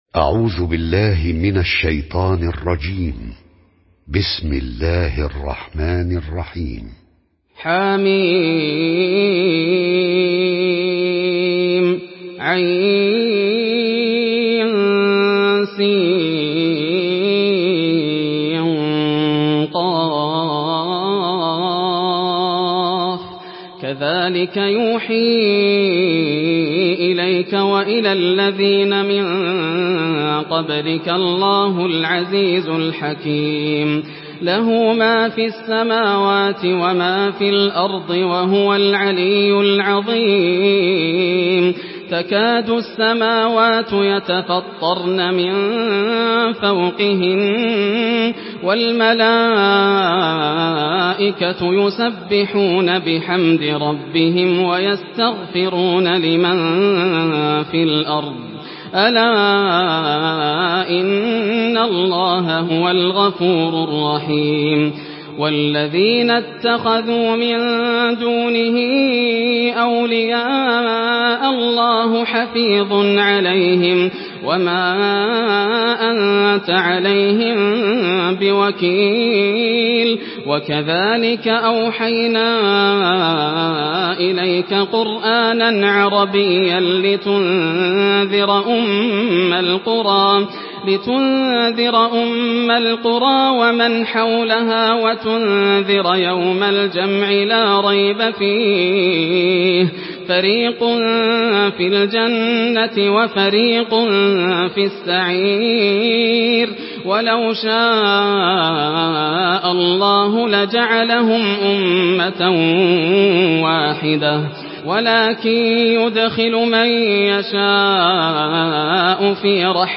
Surah Şura MP3 in the Voice of Yasser Al Dosari in Hafs Narration
Surah Şura MP3 by Yasser Al Dosari in Hafs An Asim narration.
Murattal Hafs An Asim